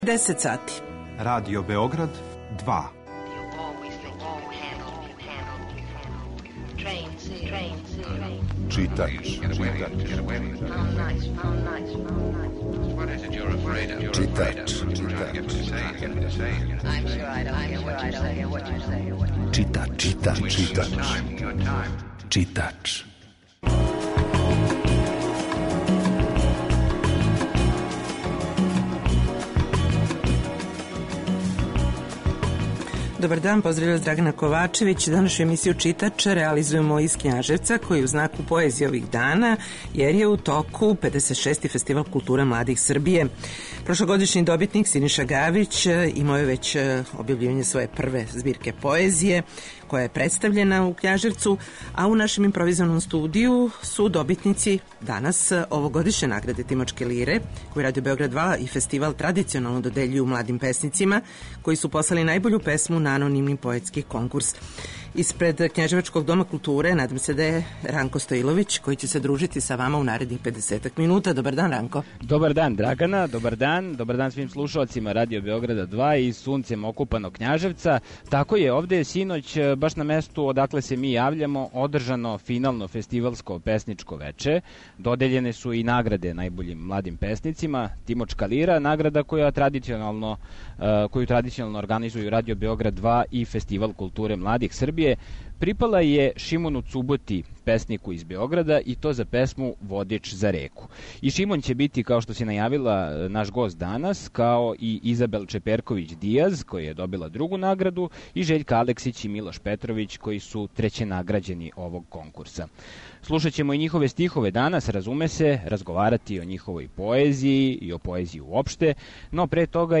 Емисију Читач данас реализујемо из Књажевца, где је у току 56. Фестивал културе младих Србије.